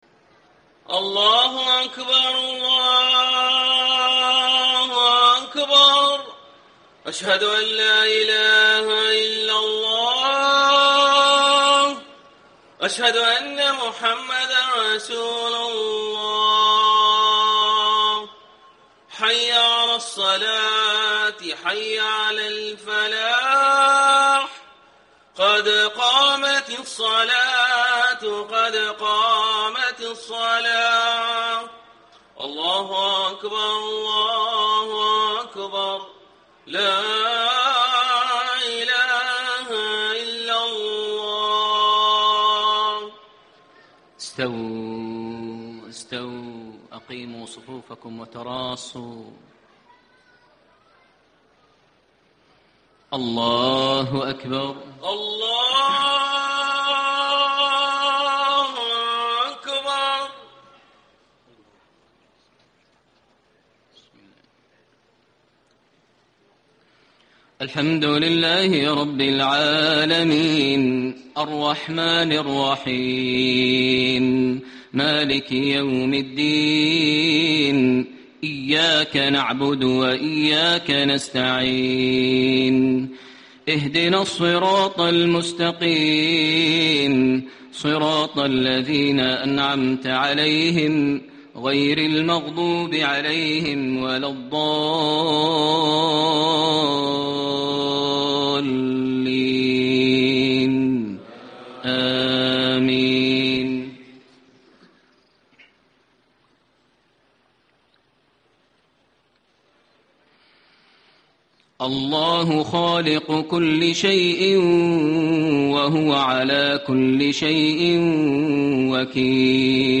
صلاة العشاء 4-6-1435 ما تيسرمن سورة الزمر > 1435 🕋 > الفروض - تلاوات الحرمين